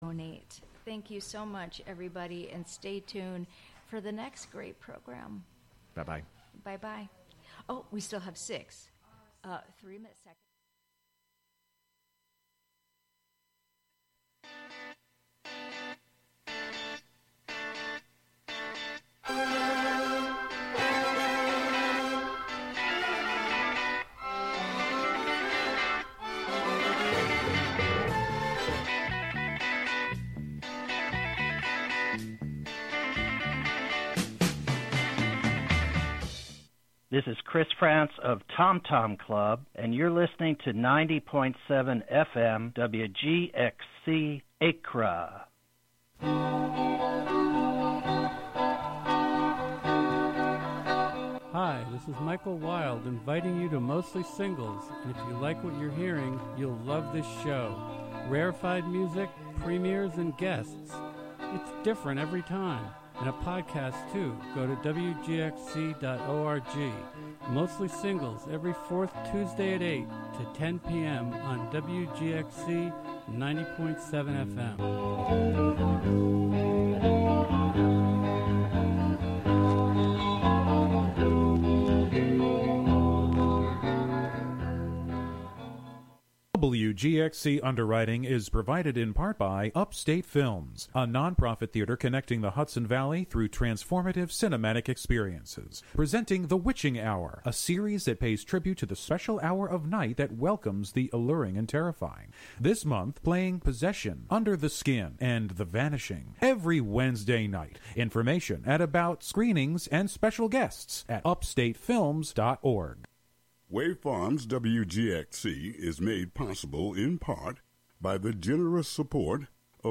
Hosted by various WGXC Volunteer Programmers.
Tune in for special fundraising broadcasts with WGXC Volunteer Programmers!